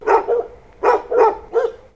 Dog
Dog.wav